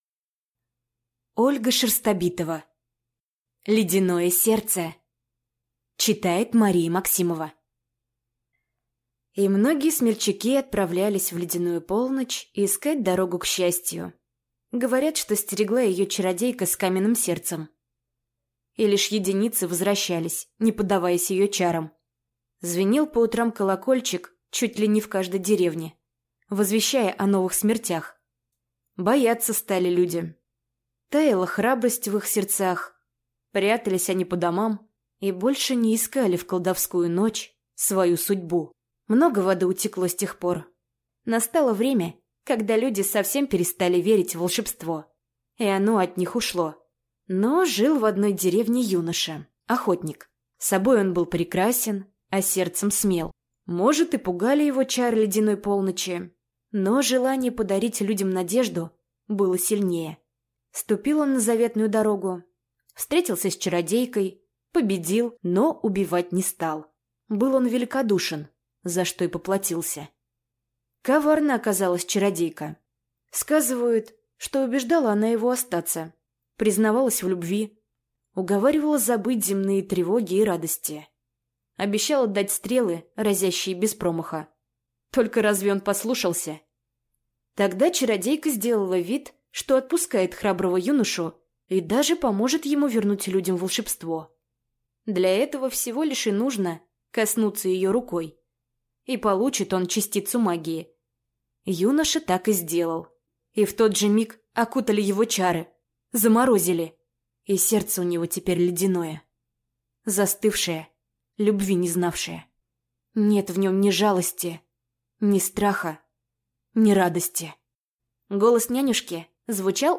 Аудиокнига Ледяное сердце | Библиотека аудиокниг